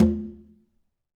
Quinto-HitN_v3_rr1_Sum.wav